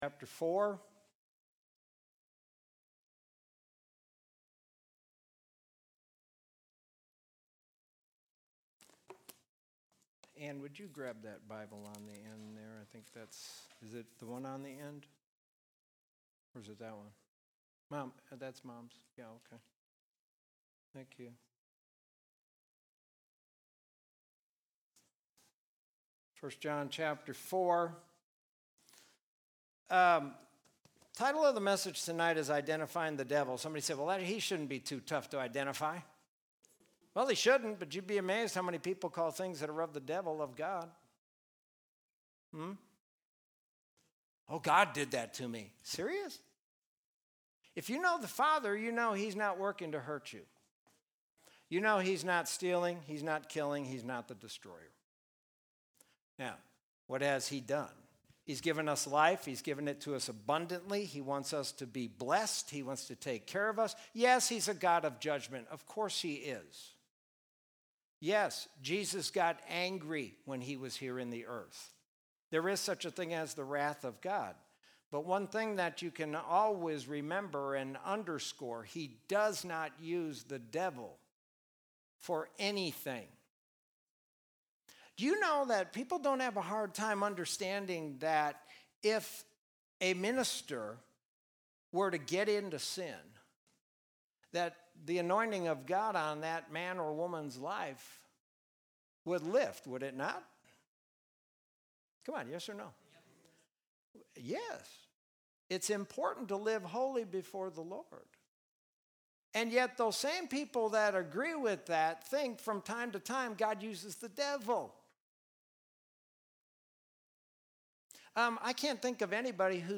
Sermon from Wednesday, June 17, 2020.